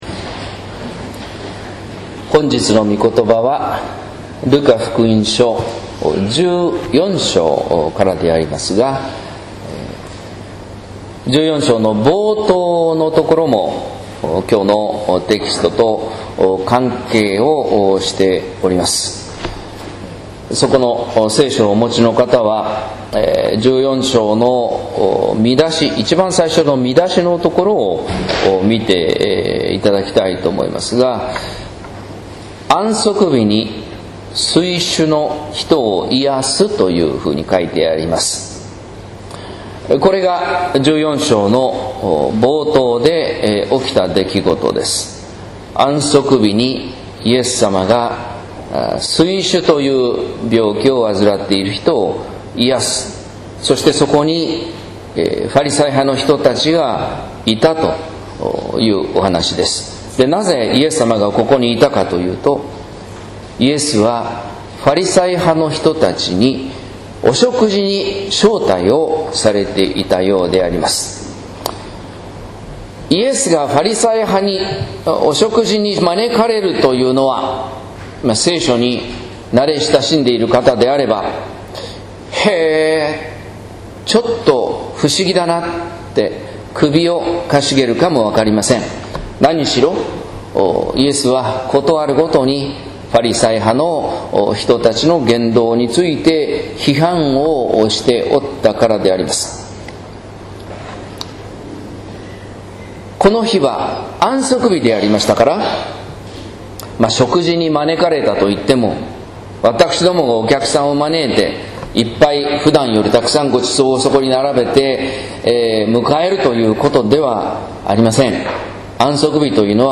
説教「へりくだる高さ」（音声版）